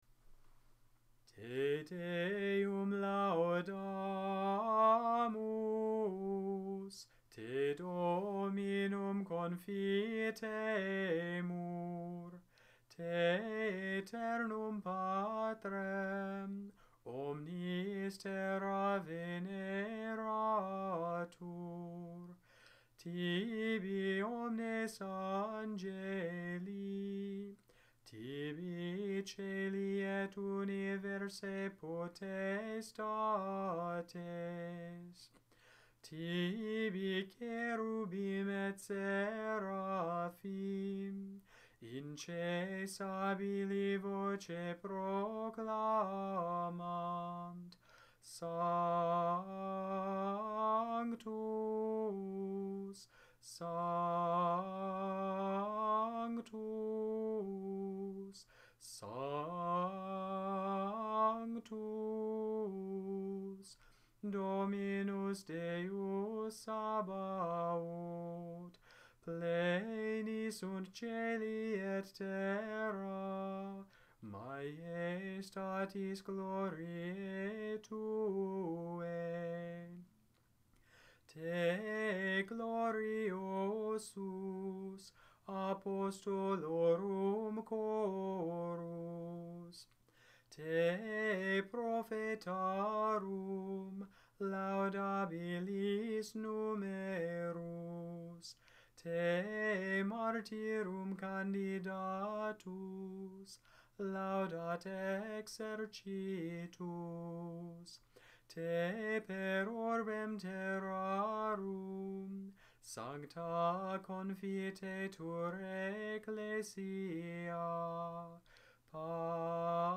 Gregorian, Catholic Chant Te Deum
Gregorian chant audios